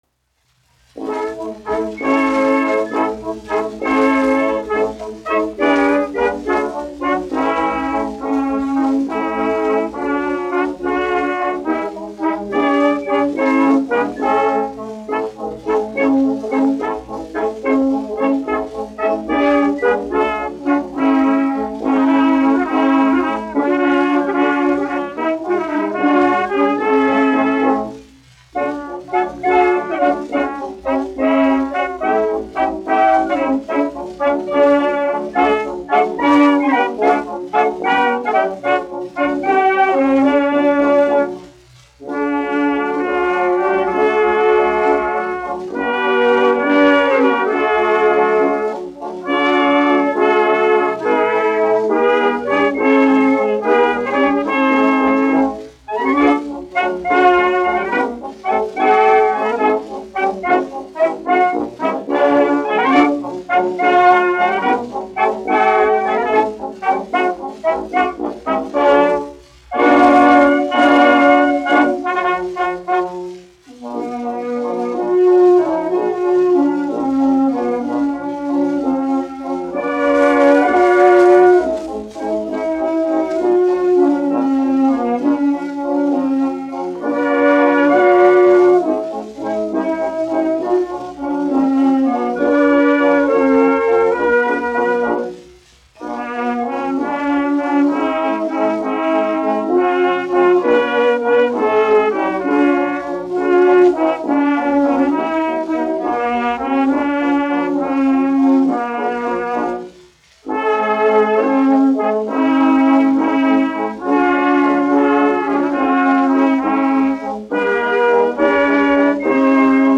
1 skpl. : analogs, 78 apgr/min, mono ; 25 cm
Valši
Tautasdziesmas, latviešu--Instrumentāli pārlikumi
Pūtēju orķestra mūzika
Skaņuplate